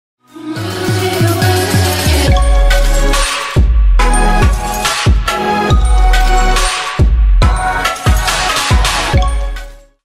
remix
Electronic
future bass
Стиль: chill trap